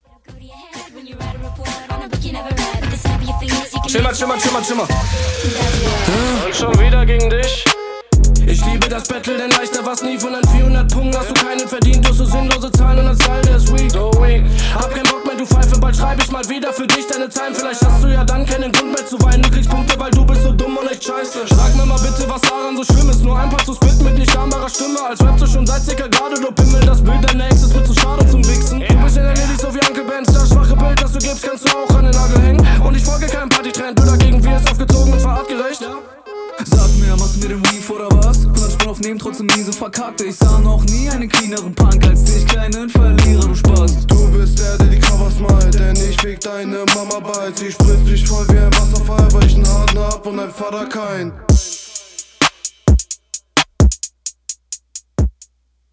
Super Beat.
Beat suckt ass.